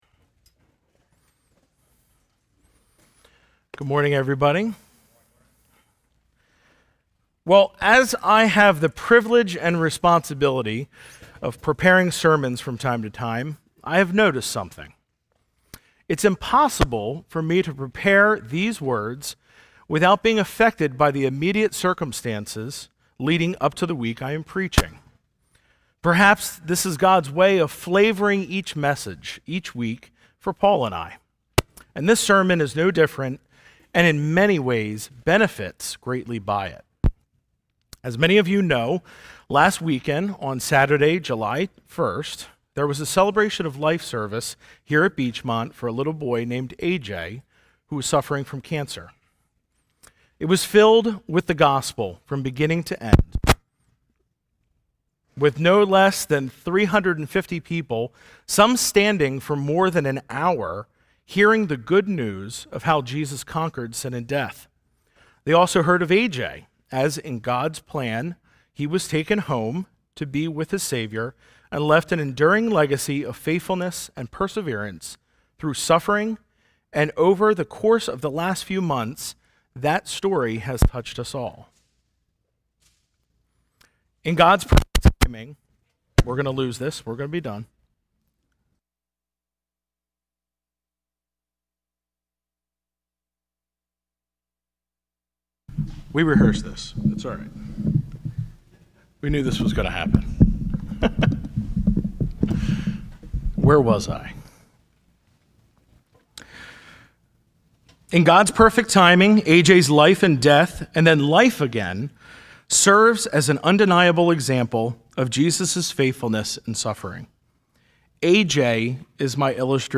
A message from the series "Exposition of Ephesians."